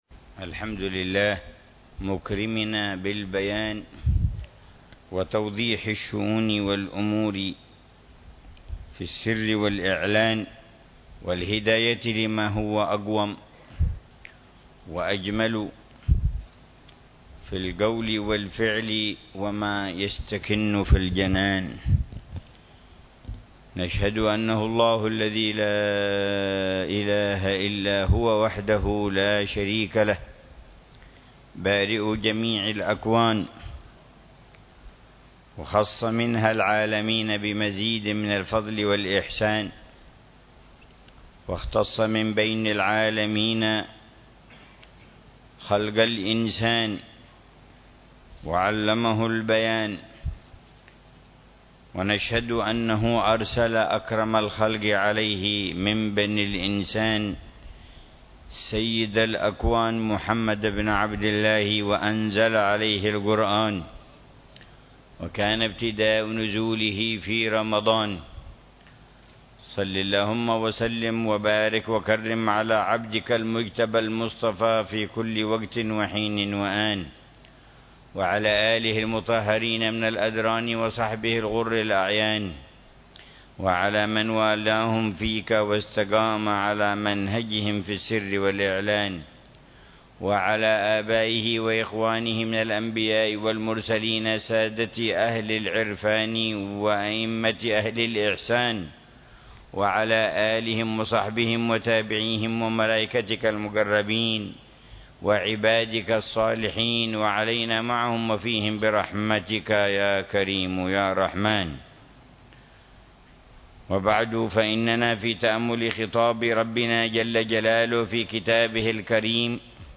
تفسير الحبيب العلامة عمر بن محمد بن حفيظ للآيات الكريمة من سورة الطلاق، ضمن الدروس الصباحية لشهر رمضان المبارك لعام 1441، من قوله تعالى: